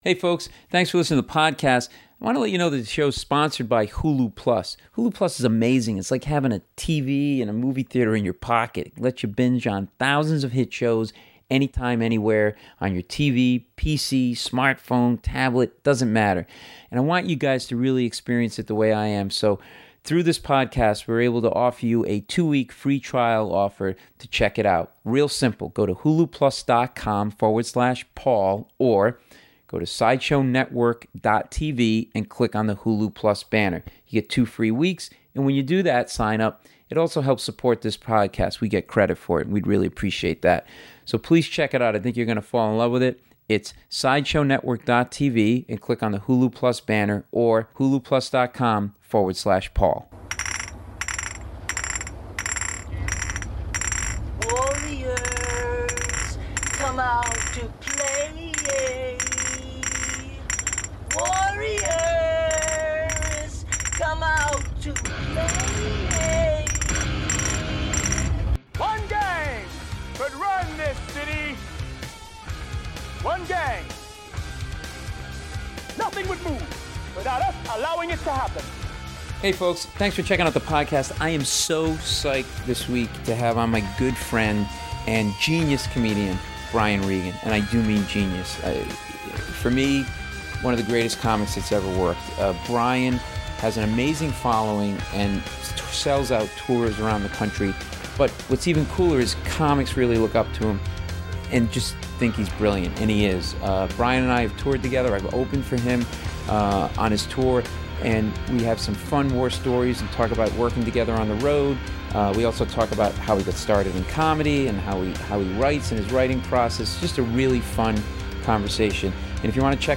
Brian Regan (Paul Mecurio interviews Brian Regan; 01 Sep 2013) | Padverb
I talk with my friend and one of the greatest stand ups to ever hit the stage, Brian Regan.